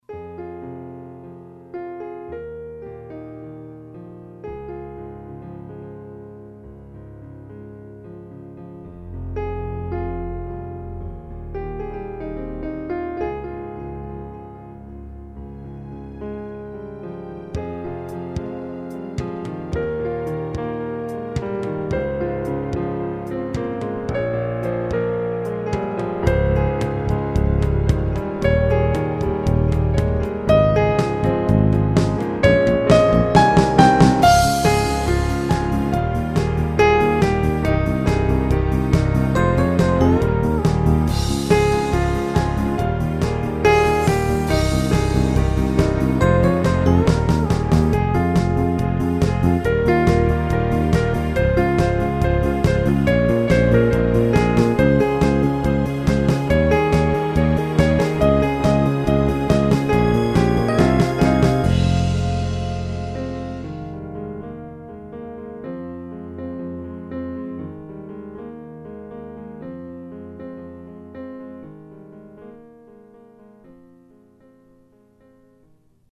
It was slated to be a normal mlawg entry several months ago, but the loud part just sounded stupid when played on the piano.